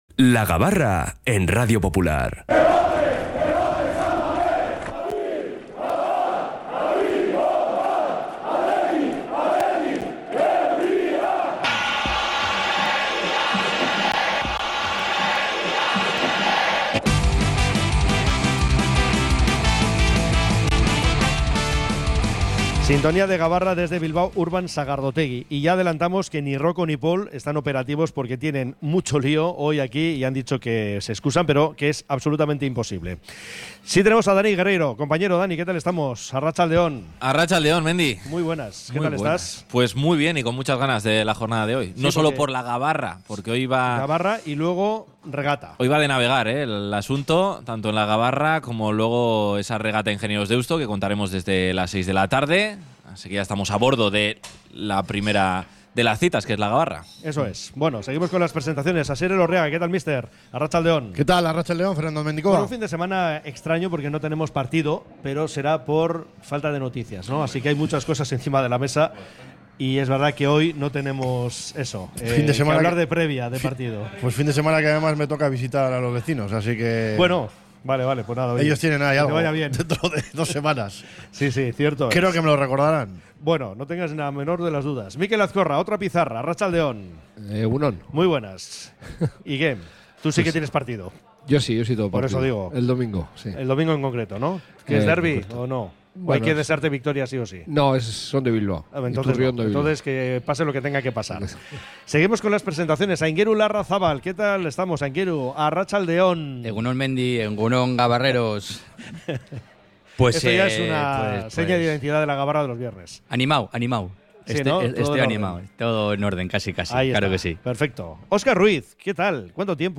Desde Bilbao Urban Sagardotegi hemos analizado, como cada viernes, toda la actualidad rojiblanca